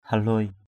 /ha-lʊoɪ/ (d.) con quay chỉ = bobine pour enrouler, le fil. baoh haluai _b<H h=l& con quay chỉ = id.